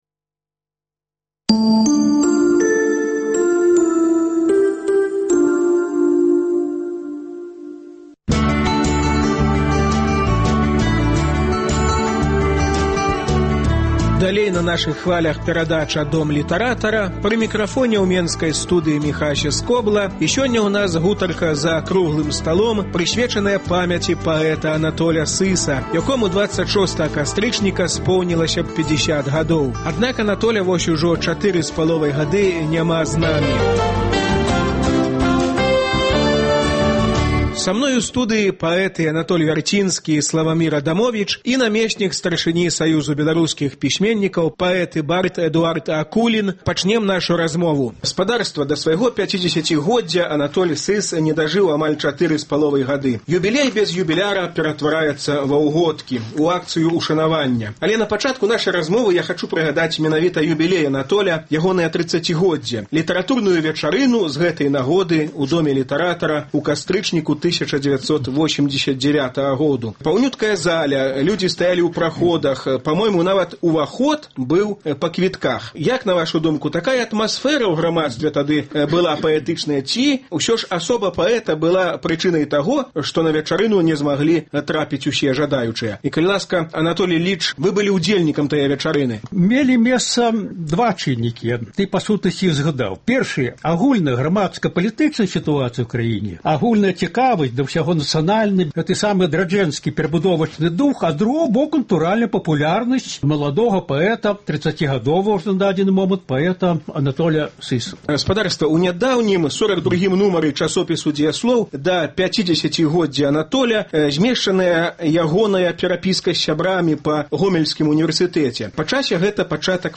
Гутарка за круглым сталом, прысьвечаная памяці паэта Анатоля Сыса, якому 26 кастрычніка споўнілася б 50 гадоў.